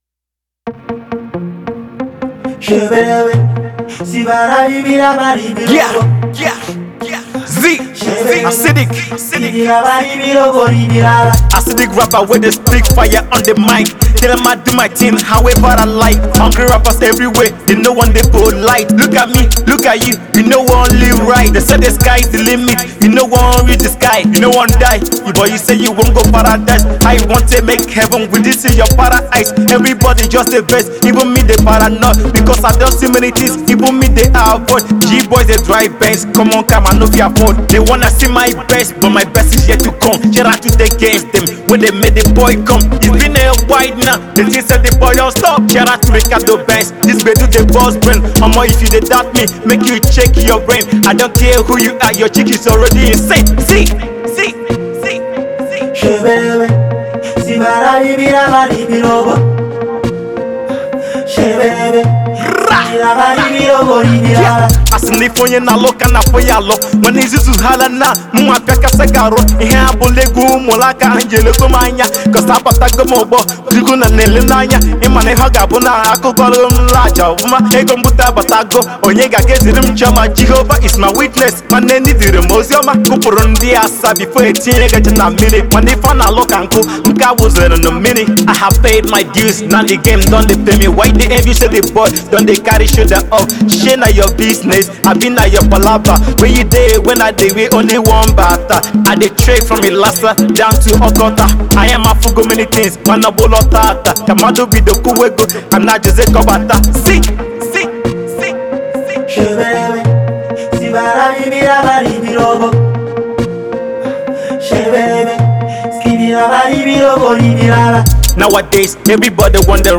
Always ready to spit bars at any given time